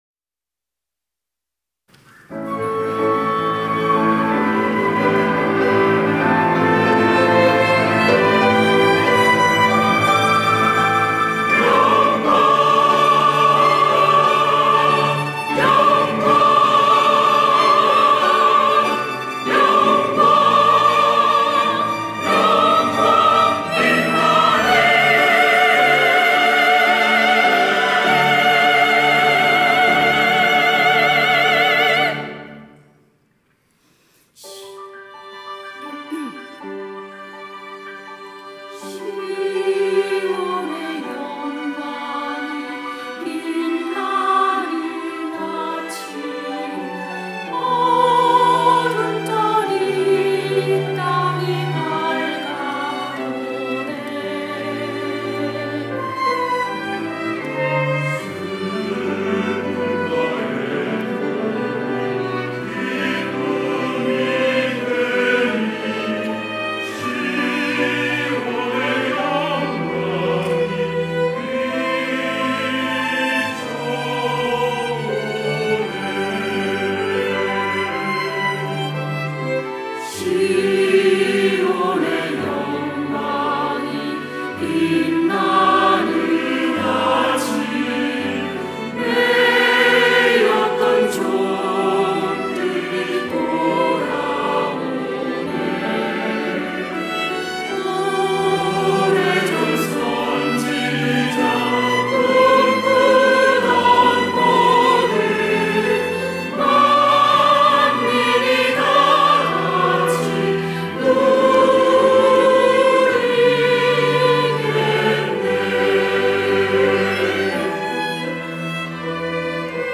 할렐루야(주일2부) - 시온의 영광이 빛나는 아침
찬양대